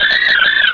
pokeemerald / sound / direct_sound_samples / cries / minun.aif
-Replaced the Gen. 1 to 3 cries with BW2 rips.